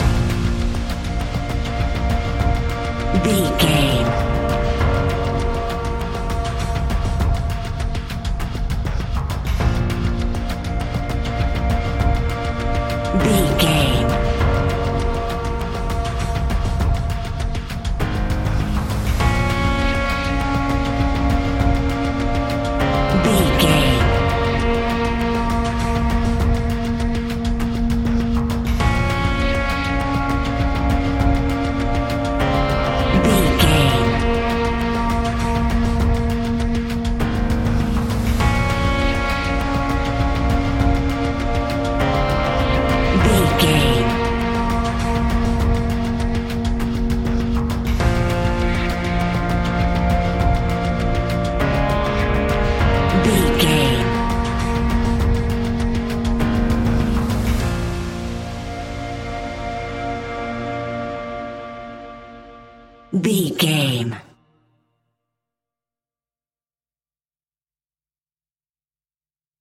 In-crescendo
Thriller
Aeolian/Minor
ominous
dark
haunting
eerie
horror music
Horror Pads
horror piano
Horror Synths